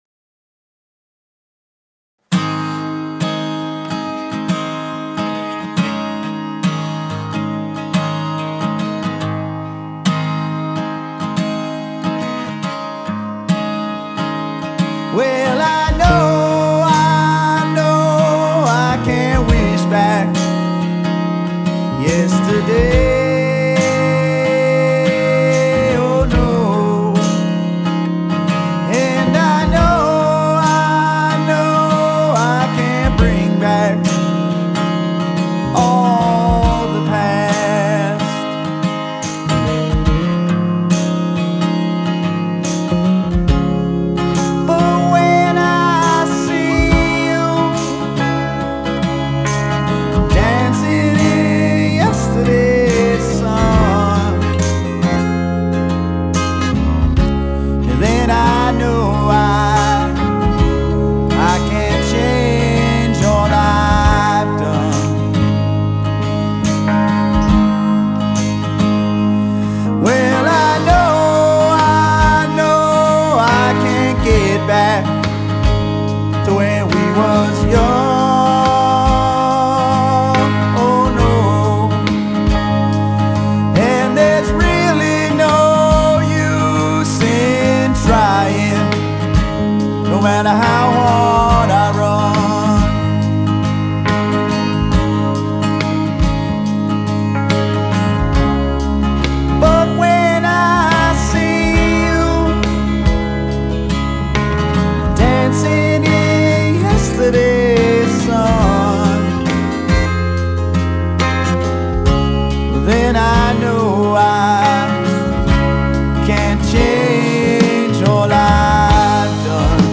Genre: Rock, Funk Rock, Blues